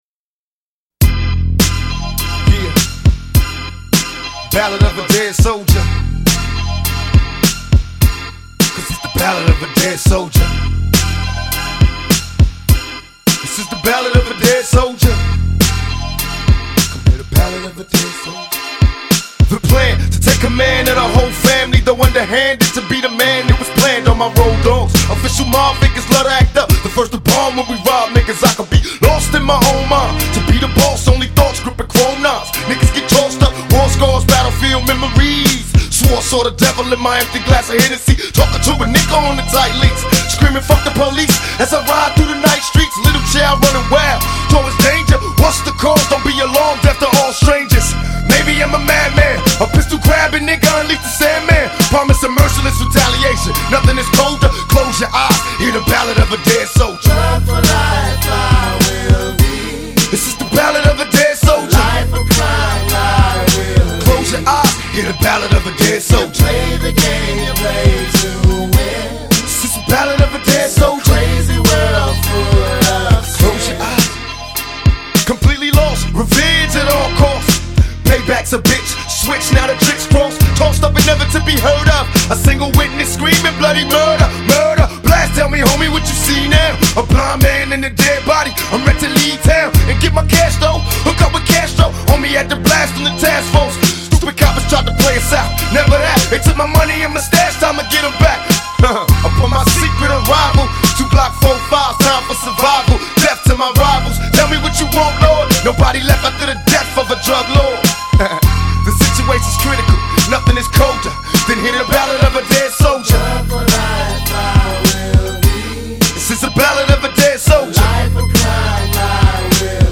ژانر: رپ